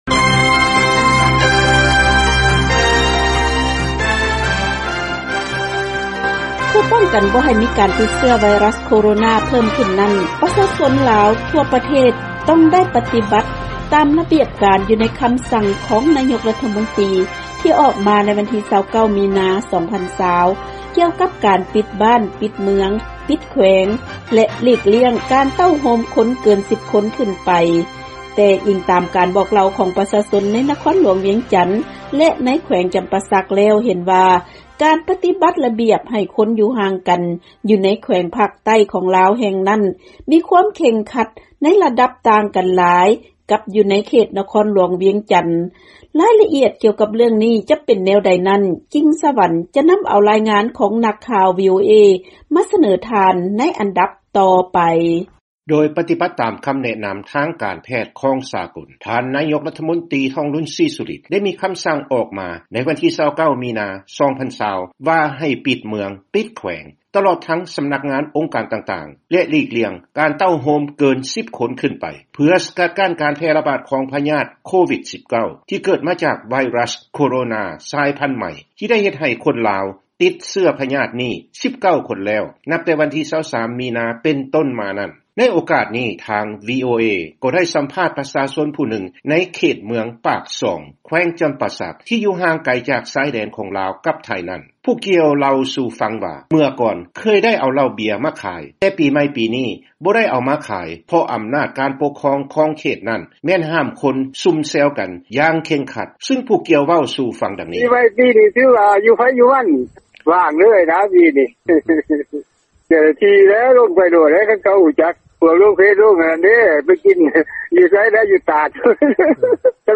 ເຊີນຟັງລາຍງານການປະຕິບັດລະບຽບໃຫ້ຄົນຢູ່ຫ່າງກັນຢູ່ໃນແຂວງຈຳປາສັກ .